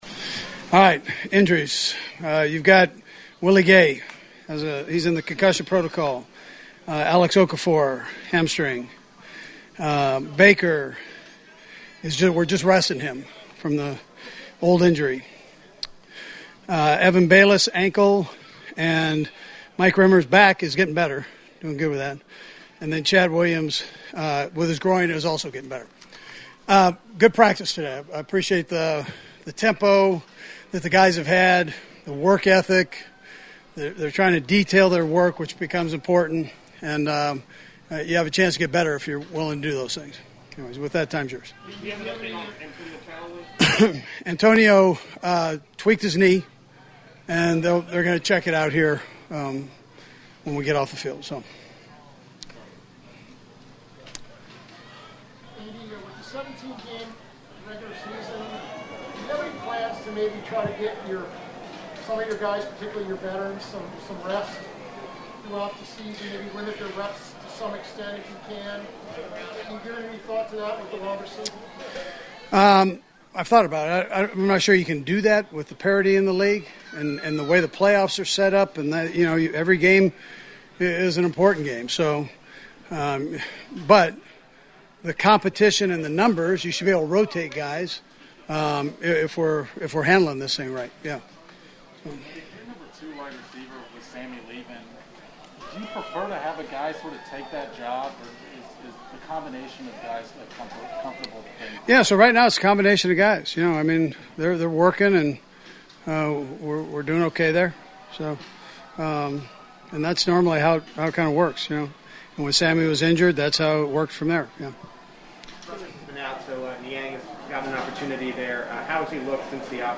Andy Reid visits with the media after Thursdays practice.